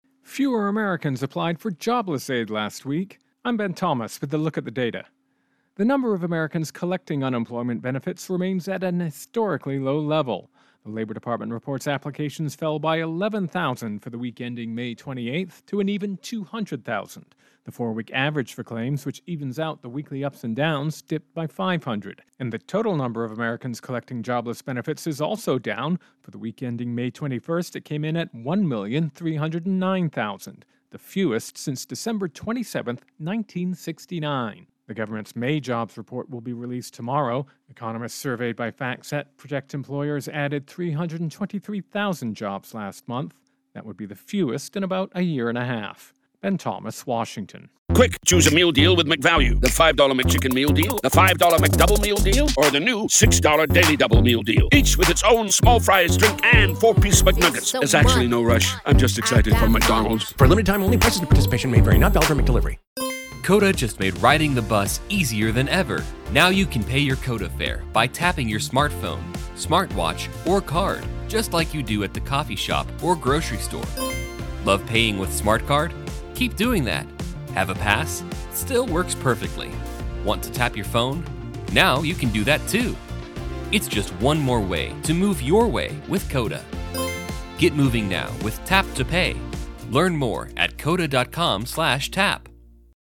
Intro and voicer "Unemployment Benefits"